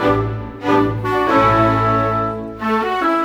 Rock-Pop 06 Orchestra 02.wav